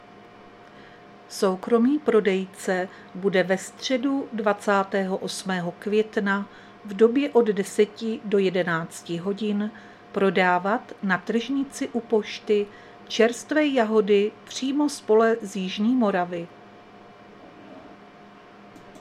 Záznam hlášení místního rozhlasu 27.5.2025
Zařazení: Rozhlas